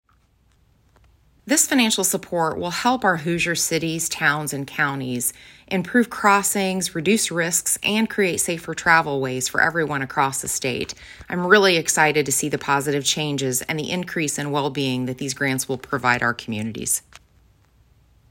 Listen to Rep. Rowray below.